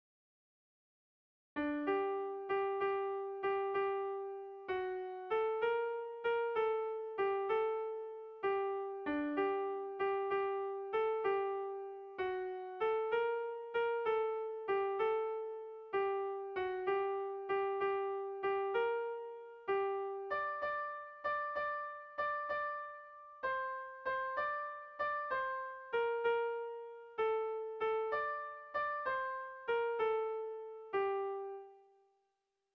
Gabonetakoa
AABD